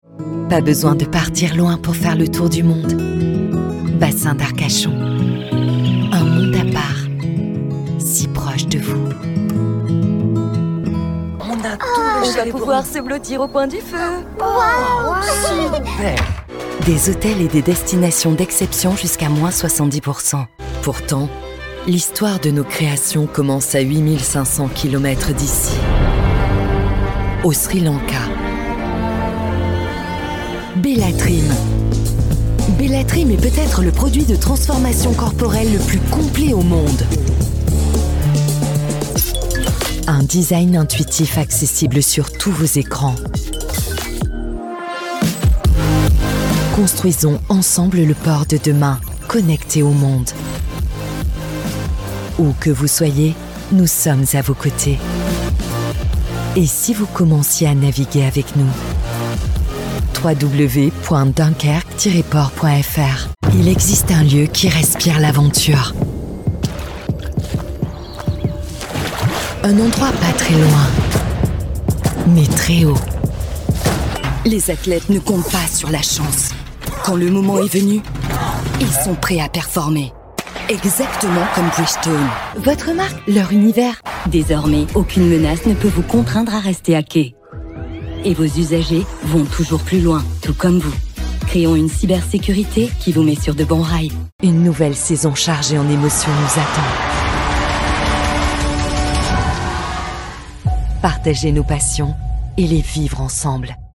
Voix off
Bandes-son